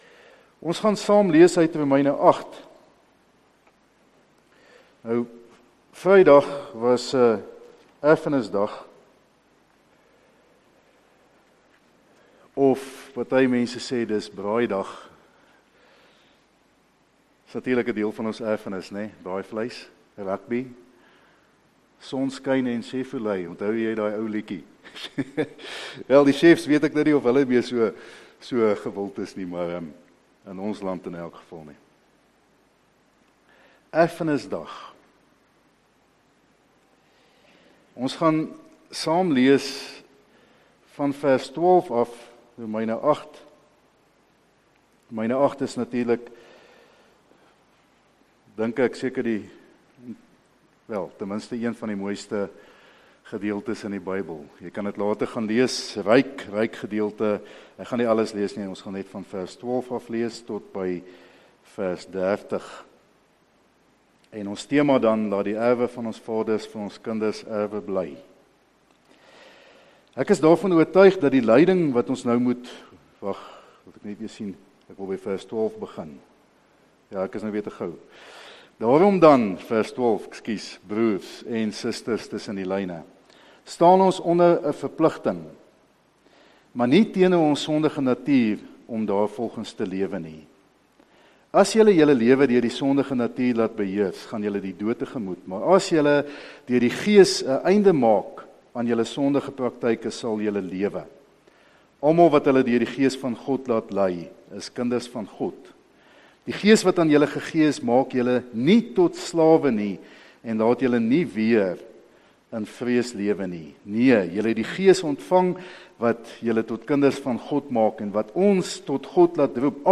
*Erediens 26 September 2021*